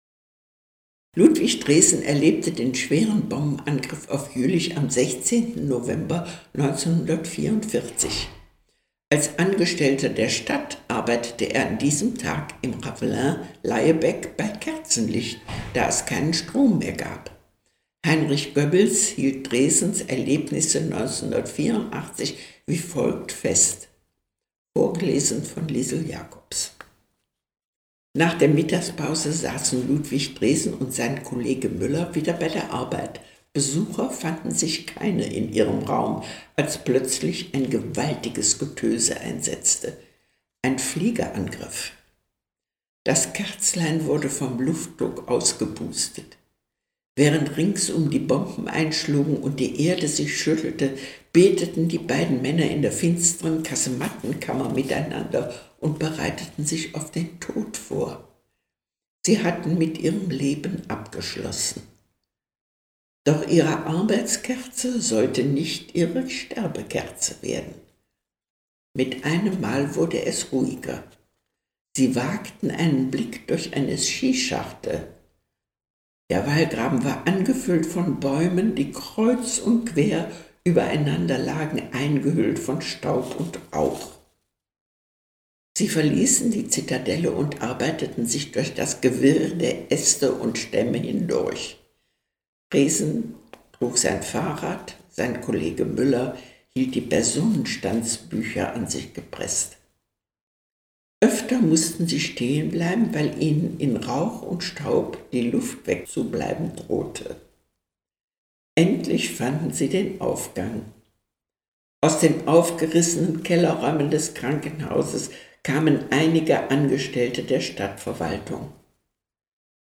Zeitzeuge Luftangriff
Zeitzeugenbericht